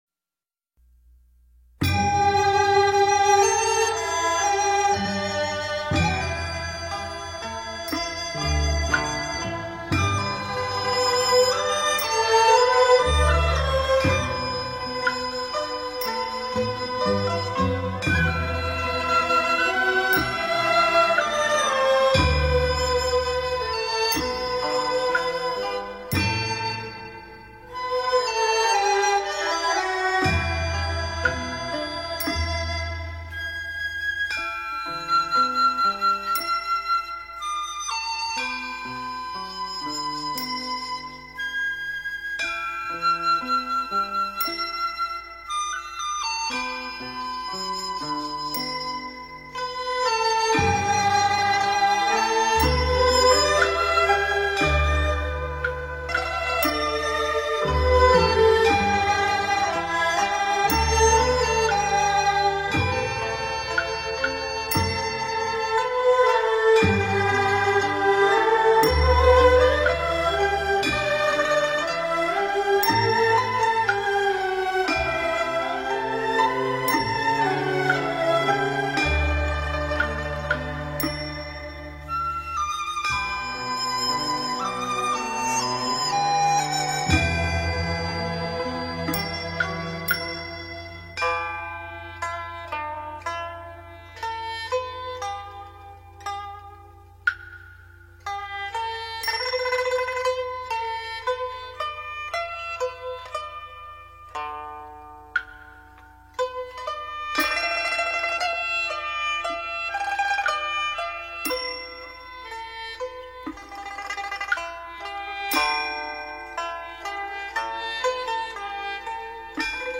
标签: 佛音诵经佛教音乐